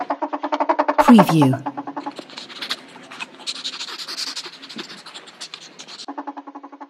На этой странице собраны разнообразные звуки хорьков: от игривого попискивания до довольного урчания.
Хорек вдыхает ароматы